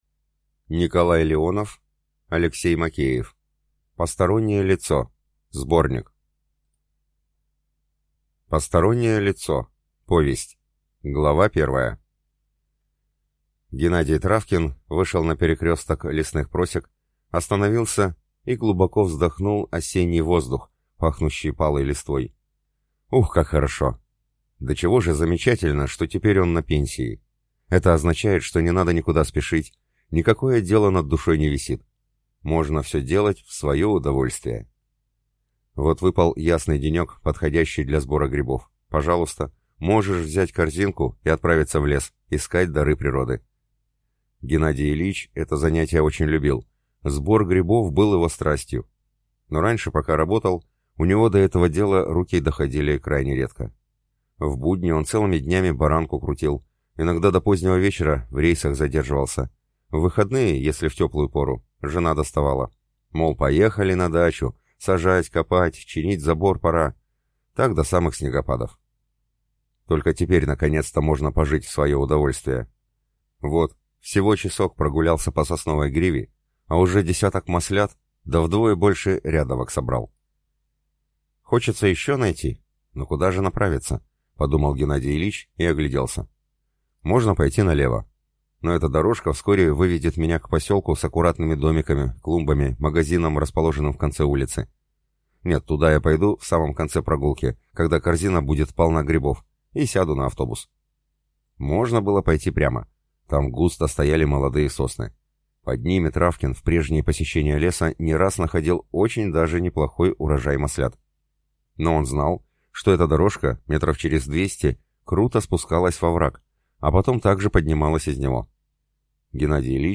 Аудиокнига Постороннее лицо | Библиотека аудиокниг